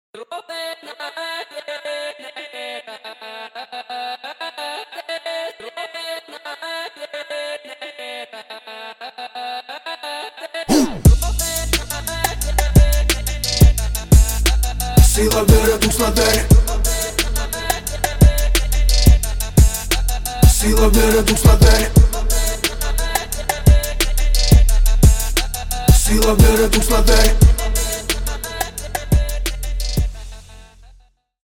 Рэп и Хип Хоп
без слов